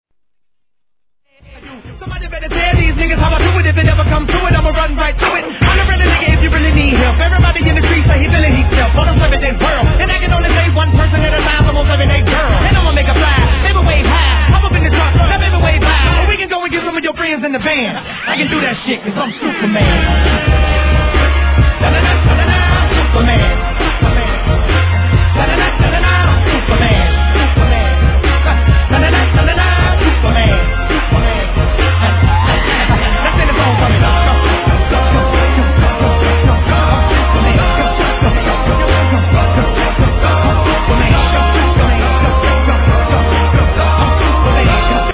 HIP HOP/R&B
(135 BPM)